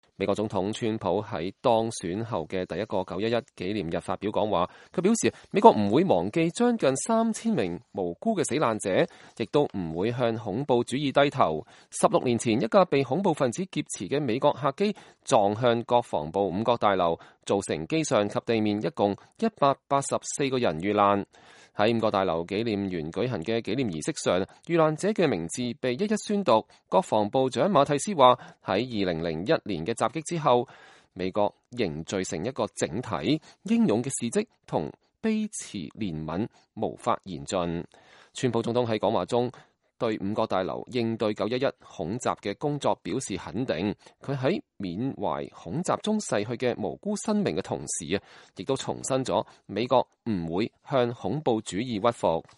川普總統參加在五角大樓9/11國家紀念園舉行的紀念儀式 (2017年9月11日)